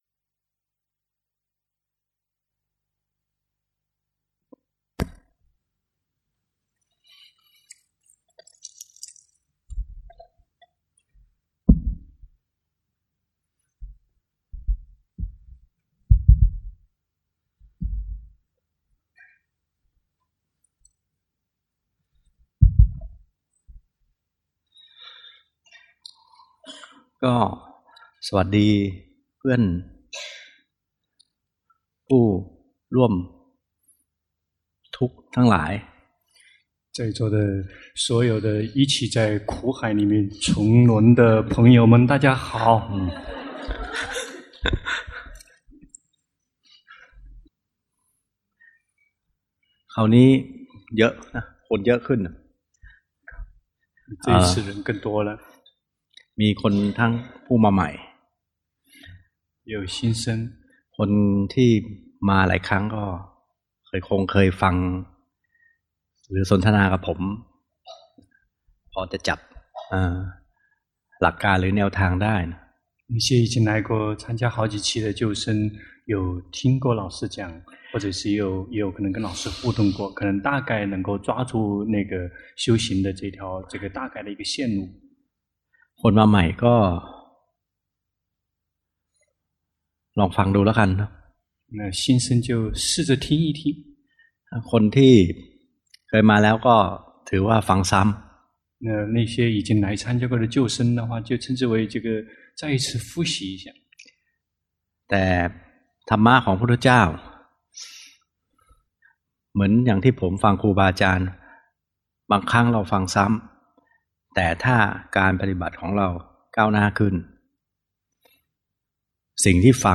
第十一屆泰國四念處禪修課程